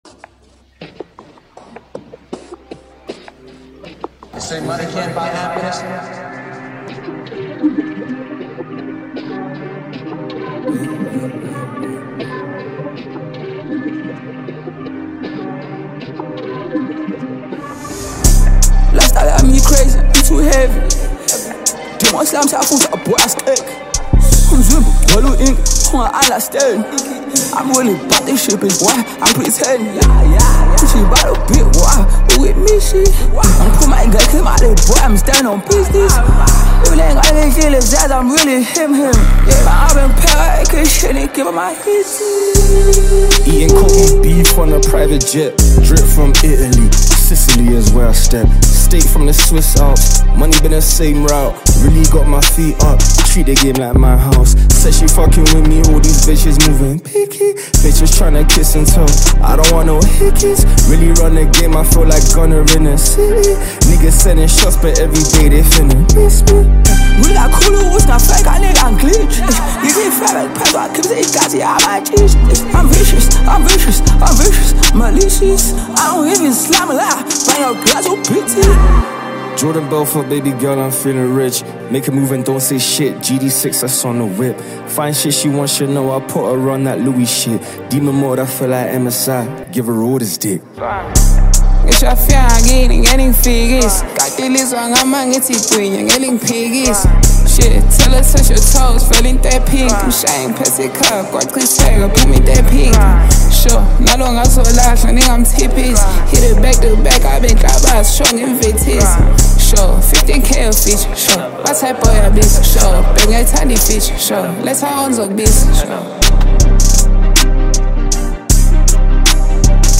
Talented vocalist
brings undeniable energy through a powerful verse